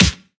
Clicky Snare Single Hit F# Key 103.wav
Royality free acoustic snare sound tuned to the F# note. Loudest frequency: 3630Hz
clicky-snare-single-hit-f-sharp-key-103-NfE.mp3